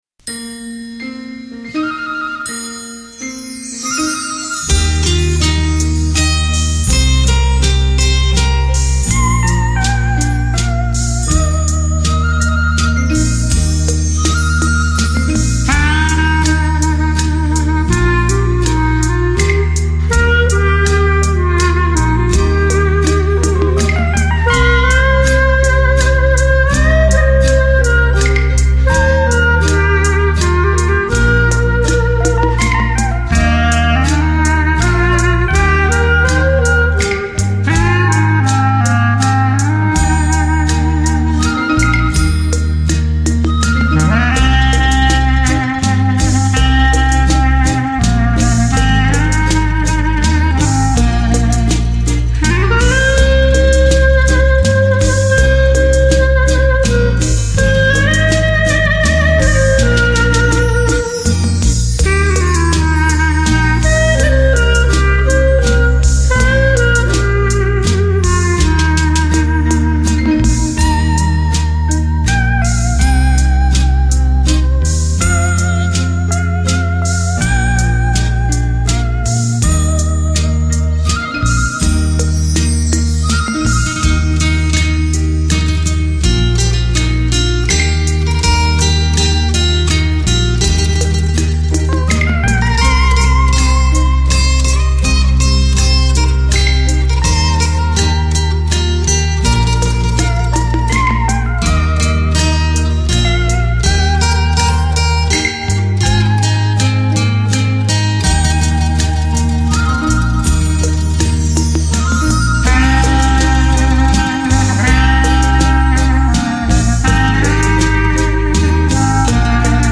专辑格式：DTS-CD-5.1声道